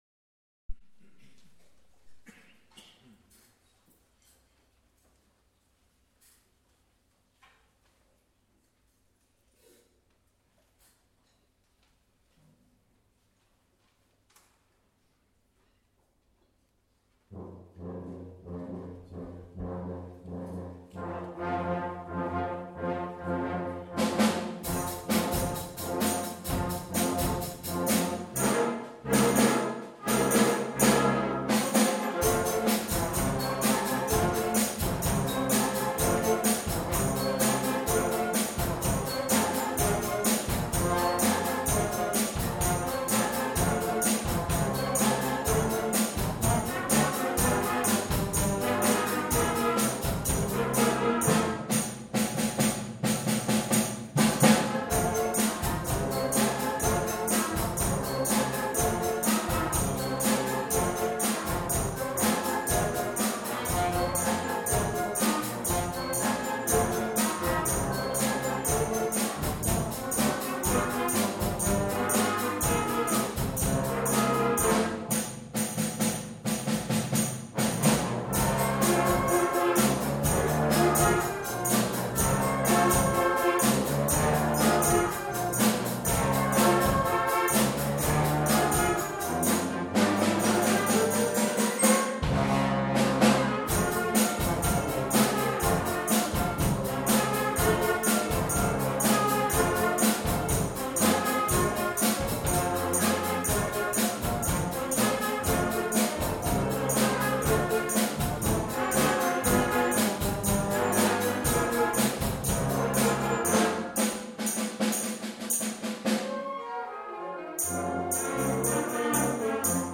Junior Wind Band - Soul Bossa Nova
A Concert of Wind, Brass and Percussion, April 2015